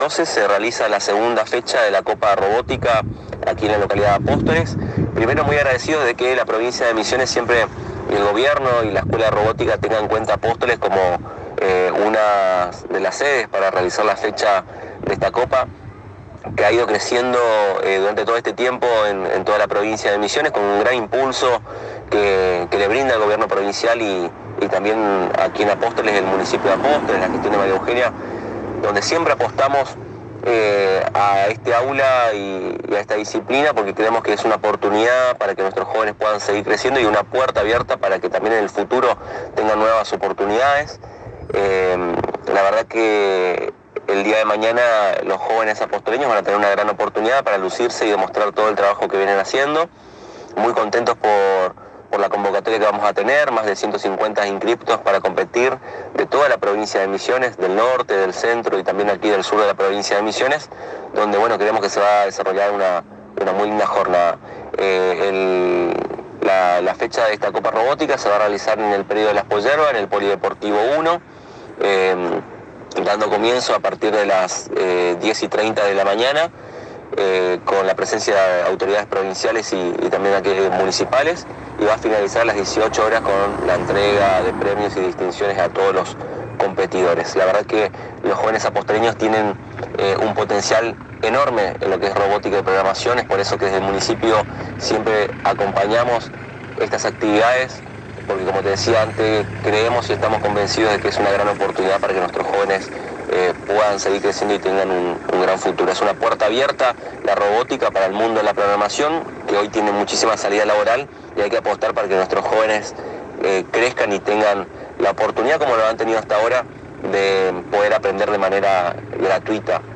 El Director de la Juventud Aldo Muñoz en diálogo exclusivo con la ANG informó sobre la realización de la segunda fecha de la Copa de Robótica a la ciudad de Apóstoles, la misma tendrá lugar el día viernes 12 de mayo del corriente en las Instalaciones de la Expo Yerba de nuestra ciudad, el Acto Inaugural esta previsto para las 10 30 horas, a las 11 30 será el inicio del primer Round de todas las categorías, a las 13 horas el segundo Round, a las 15 será el tercer Round y a las 17 está prevista las respectivas finales, cerrando el evento a las 18 horas.